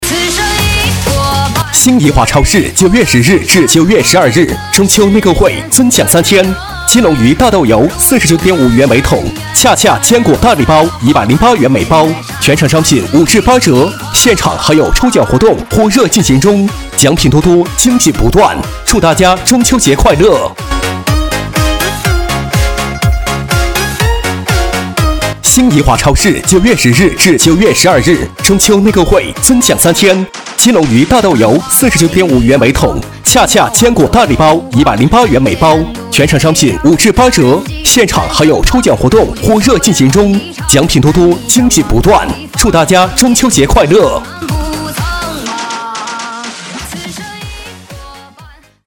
【男28号促销】新怡华超市.mp3